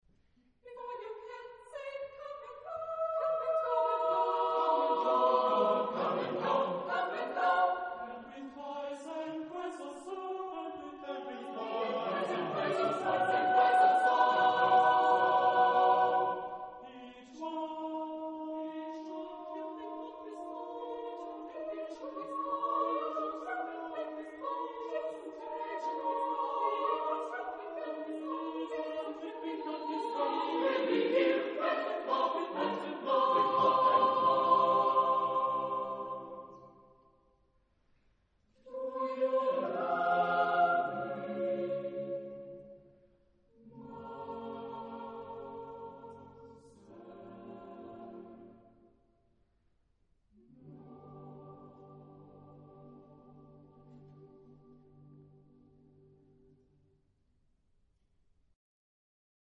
Type of Choir: SSAATTBB  (8 mixed voices )